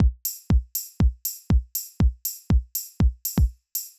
ITA Beat - Mix 4.wav